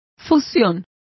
Complete with pronunciation of the translation of fusion.